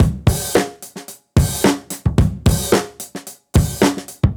Index of /musicradar/dusty-funk-samples/Beats/110bpm
DF_BeatD_110-04.wav